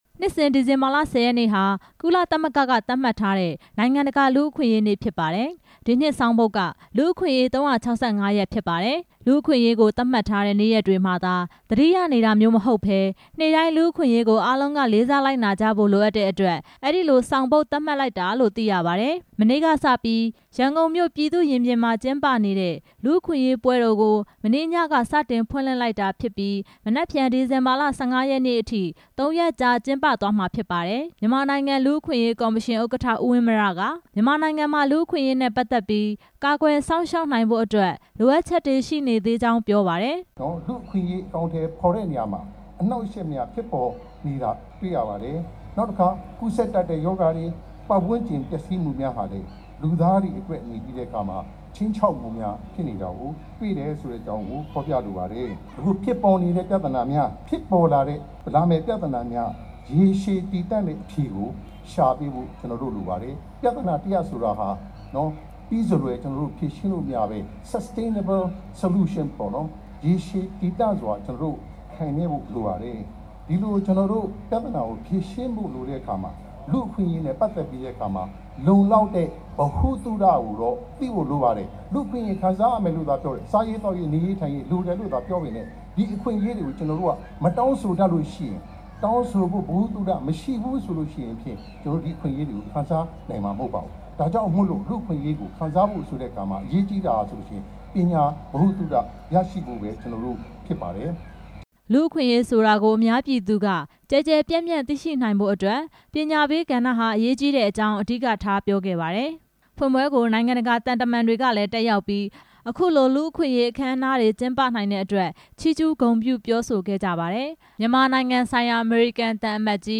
မနေ့က ဖွင့်ပွဲအခမ်းအနားမှာ နိုင်ငံတကာသံအမတ်ကြီးတွေနဲ့ မြန်မာနိုင်ငံ အမျိုးသားလူ့အခွင့်အရေး ကော်မရှင် ဥက္ကဌတို့လည်း တက်ရောက် စကားပြောကြားခဲ့ပါတယ်။